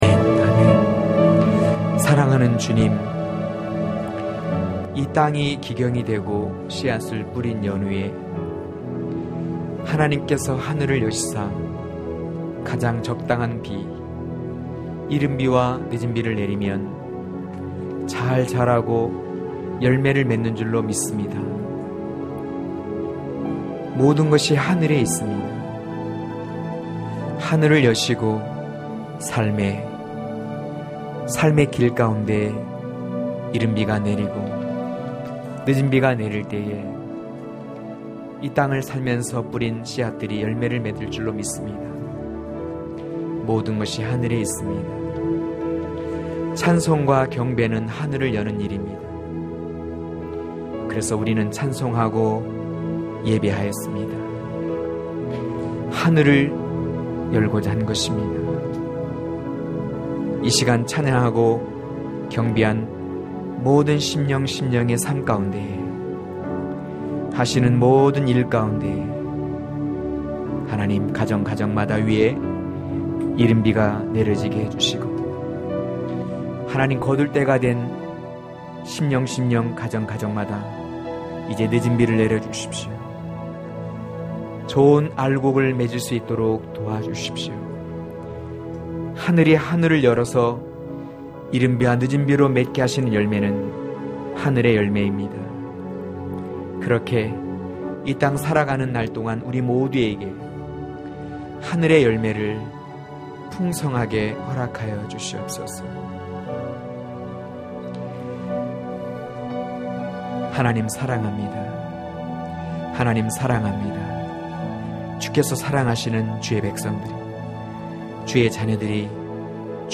강해설교 - 17.새 예루살렘의 신부(아7장10-13절)